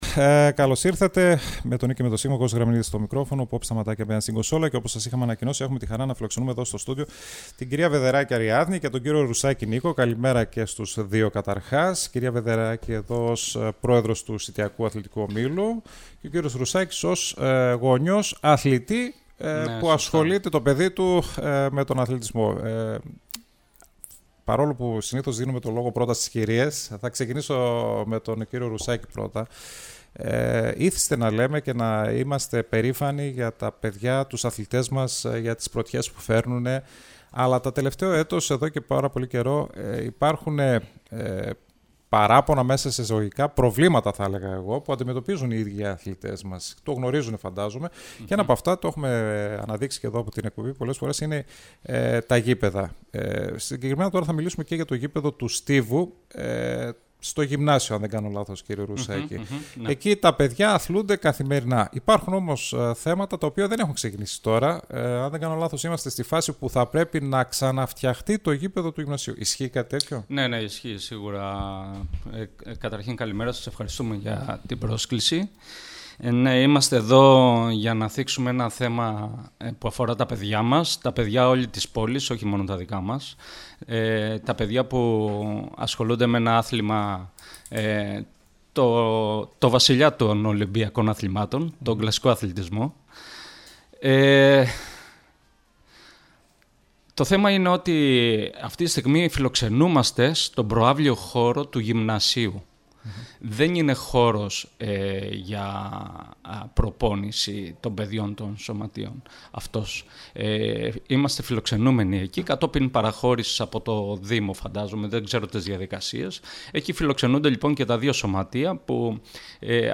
ΣΥΝΕΝΤΕΥΞΕΙΣ